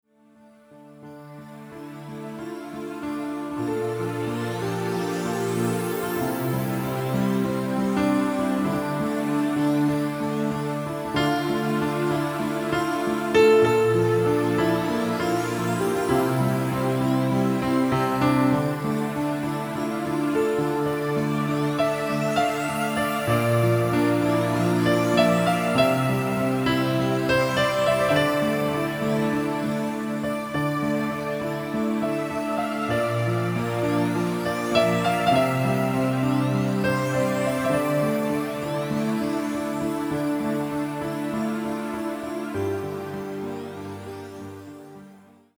Superb (stereo) samples, no digital noise and extremely realistic.
These MP3's were recorded on a Tyros II, using the built in harddisk recorder, edited and encoded with a computer.
Piano layered with pads